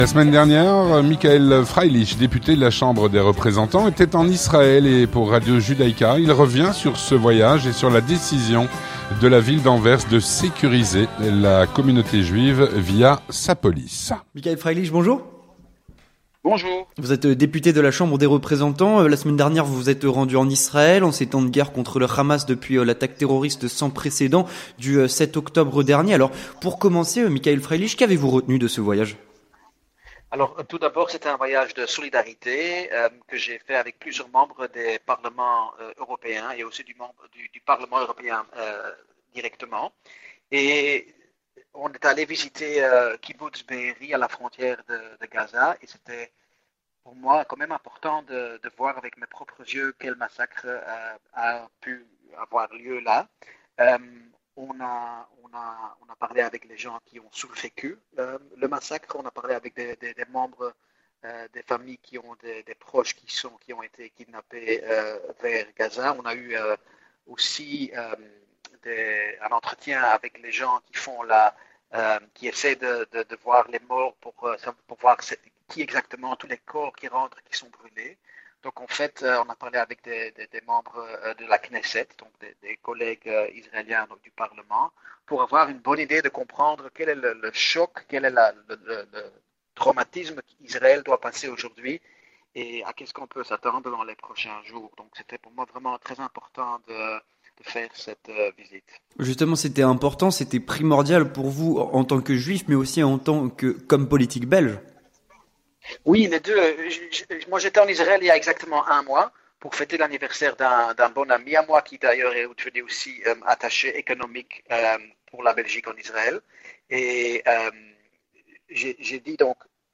Avec  Michael Freilich, député de la chambre des représentants
Présenté par